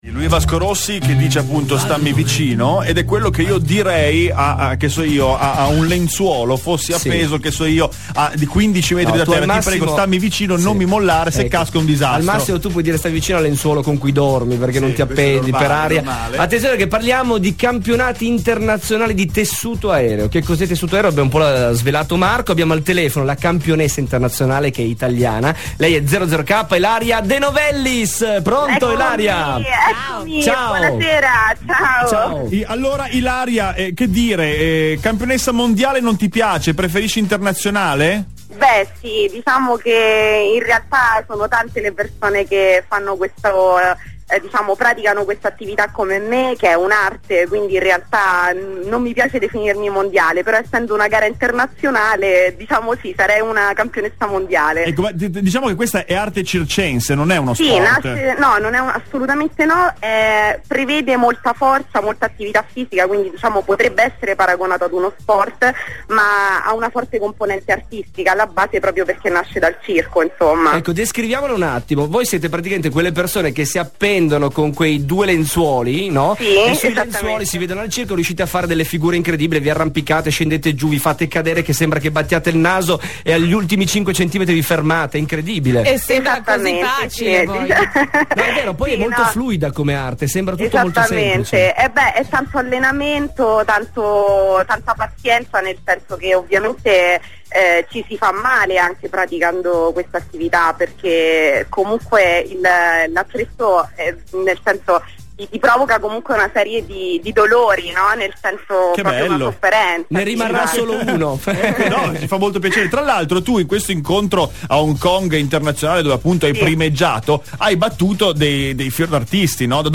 IntervistaRadioKiss.mp3